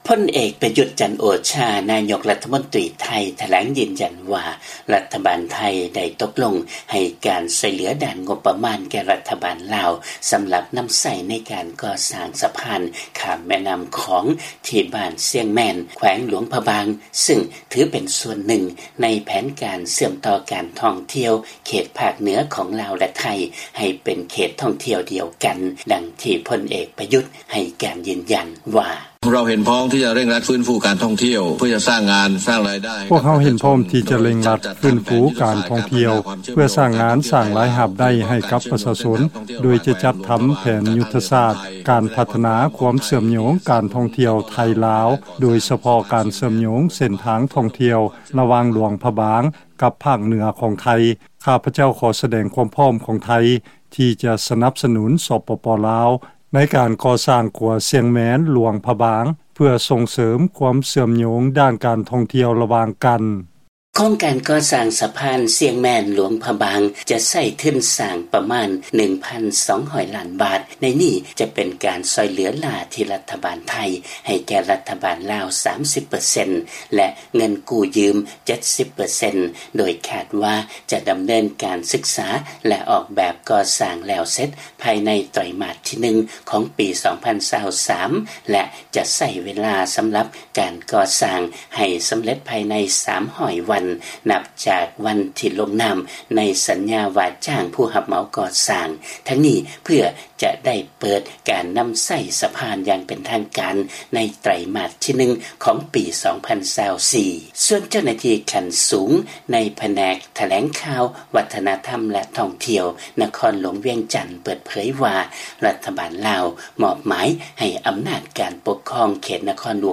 ເຊີນຟັງລາຍງານກ່ຽວກັບການຊ່ວຍເຫລືອຂອງໄທເພື່ອພັດທະນາການທ່ອງທ່ຽວຂອງລາວໃຫ້ເຊື່ອມໂຍງກັບໄທ